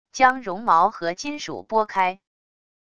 将绒毛和金属拨开wav音频